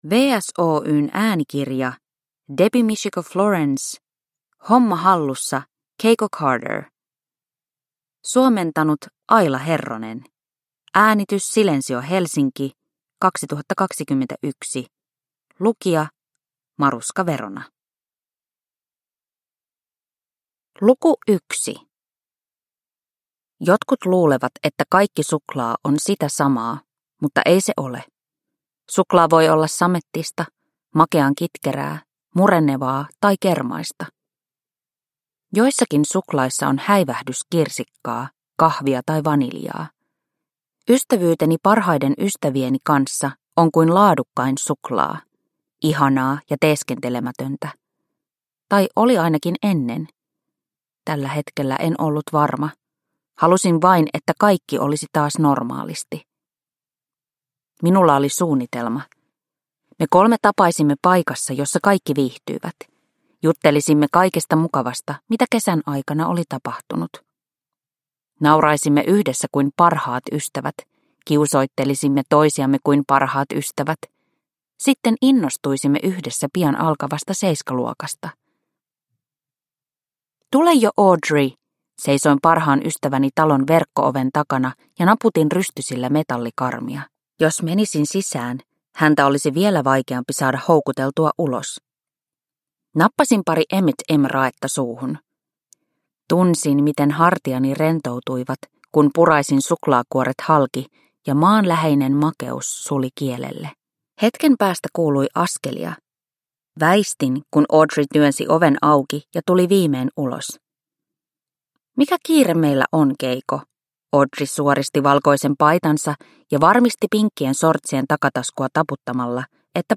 Homma hallussa, Keiko Carter – Ljudbok – Laddas ner